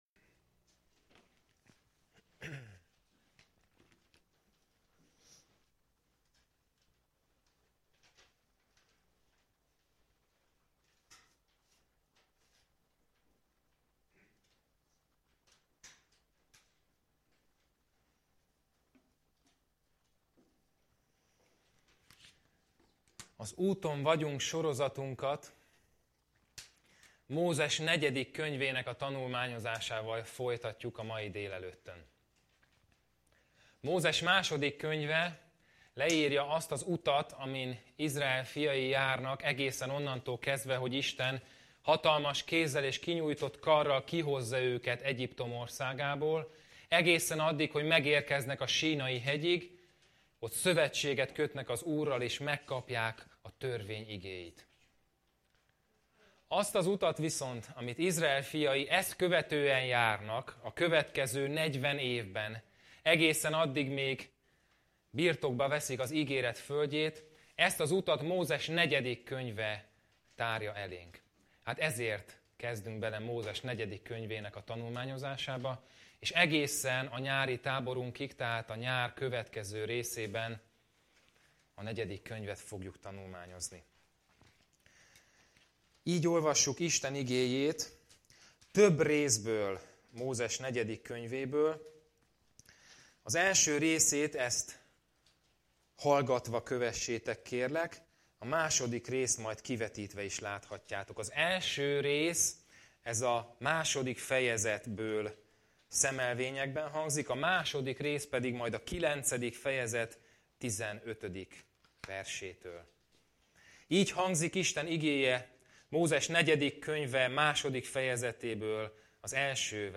Igehirdetés Fájlok letöltése Bulletin « Dicsőségének megismerése Hova húz a szíved?